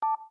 Index of /phonetones/unzipped/LG/GS390-Prime/DialPad sounds/Beep
DialPad7.wav